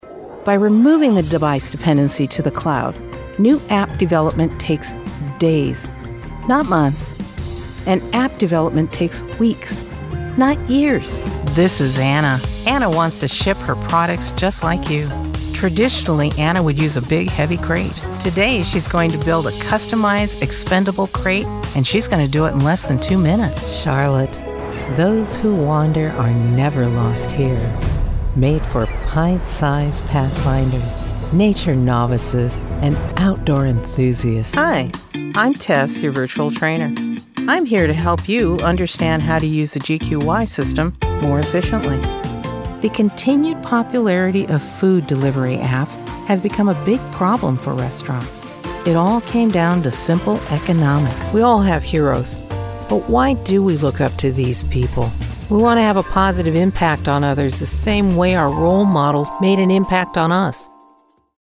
Narration Demo